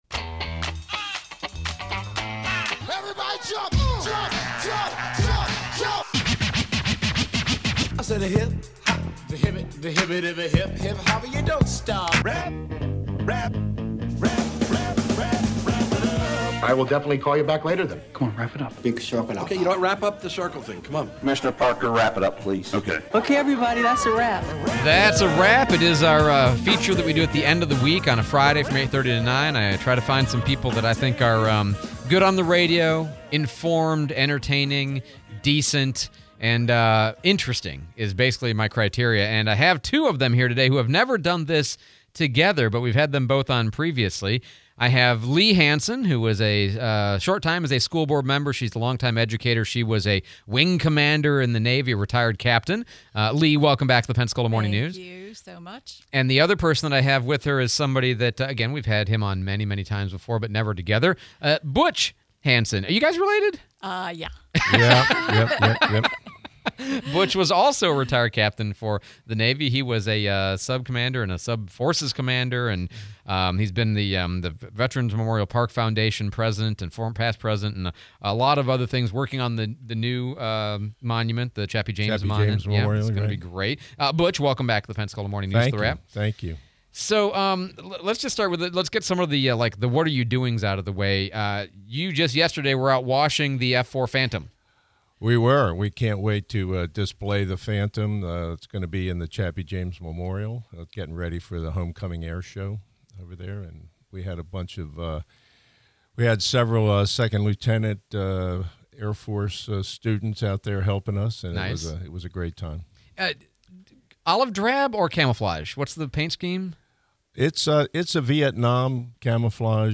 10/20/2023 - Wrap Up Interview